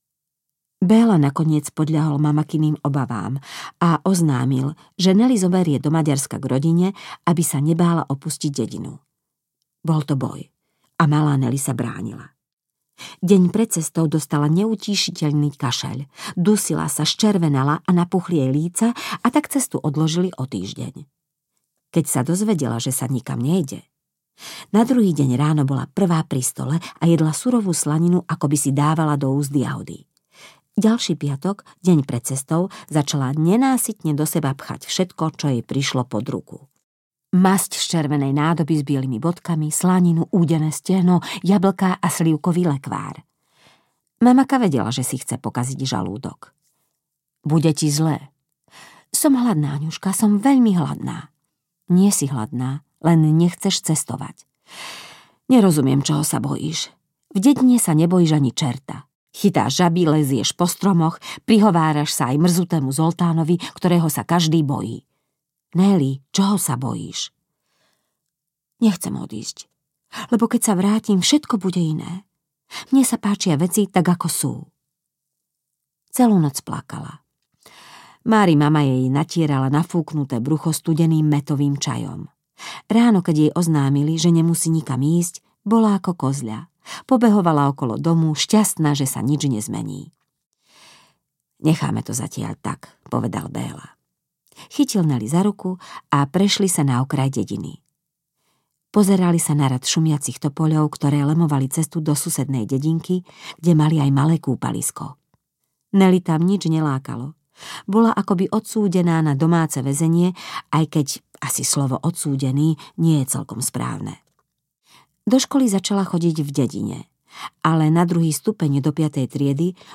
Kornélie audiokniha
Ukázka z knihy